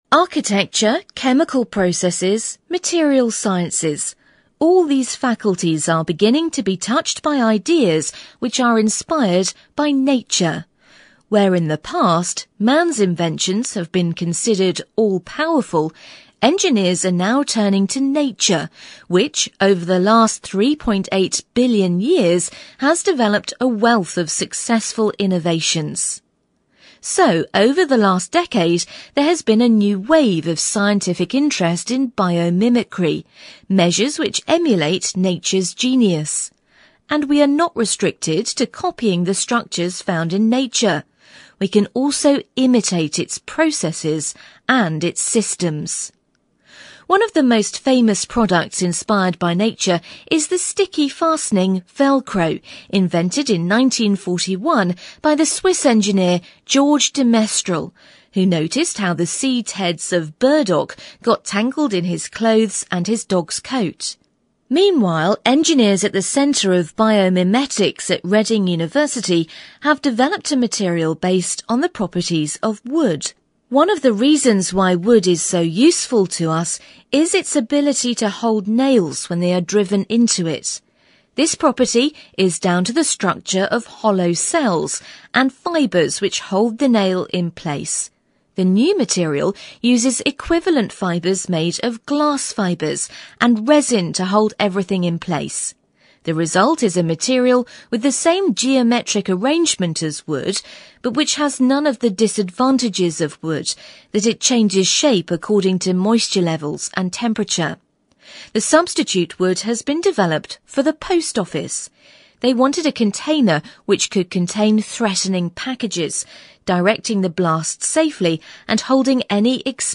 Task II You will hear a radio program about Biomimicry – the science of copying nature to create new technologies.